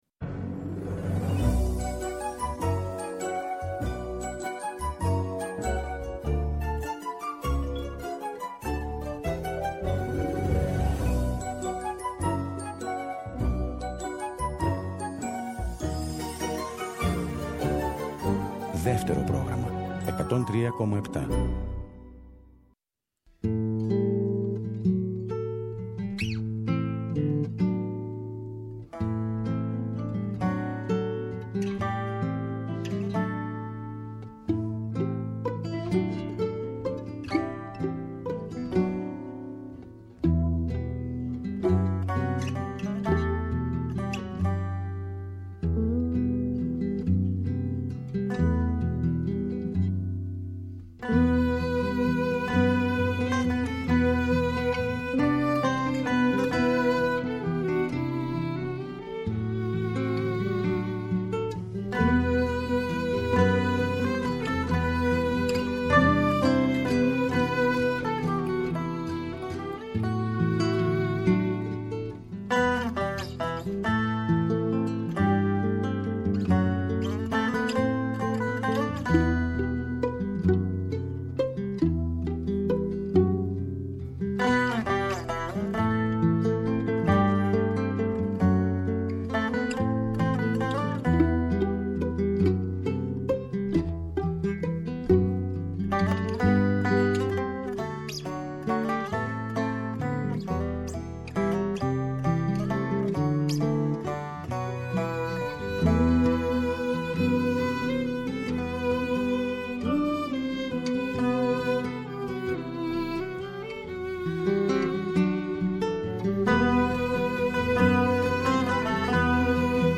Εκπομπές Μουσική